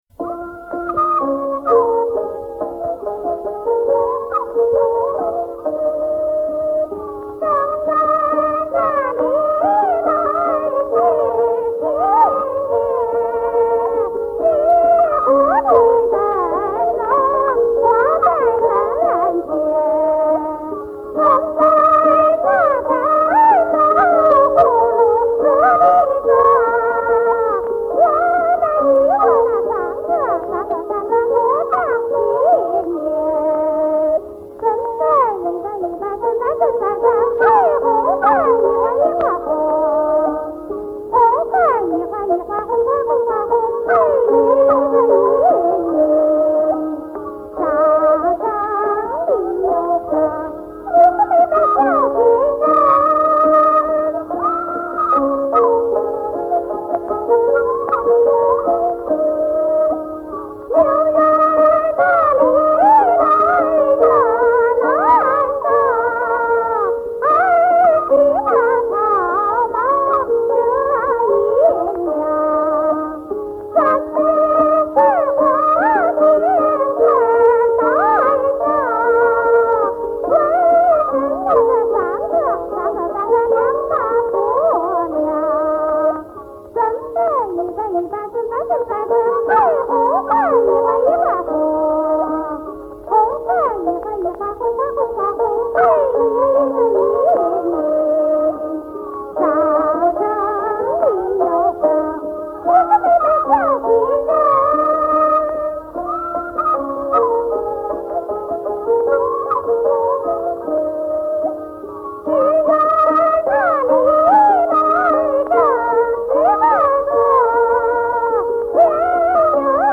山西民歌
1957年录音